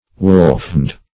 Wolfhound \Wolf"hound`\, n. (Zool.)